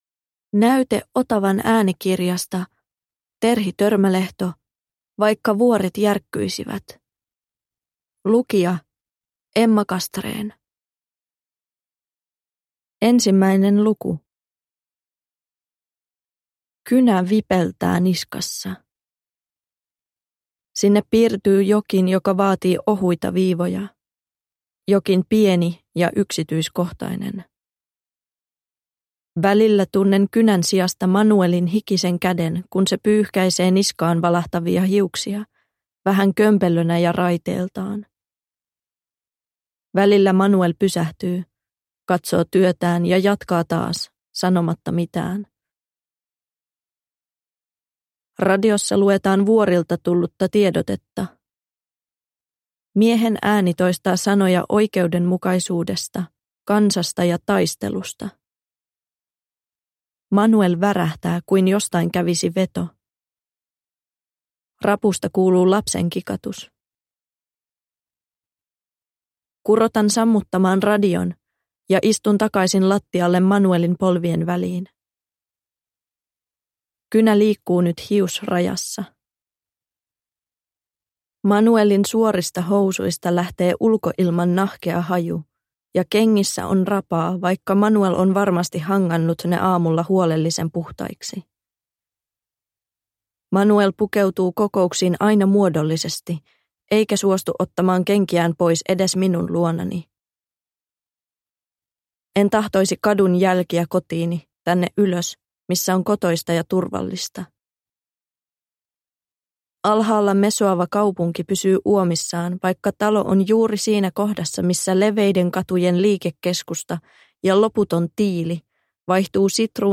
Vaikka vuoret järkkyisivät – Ljudbok – Laddas ner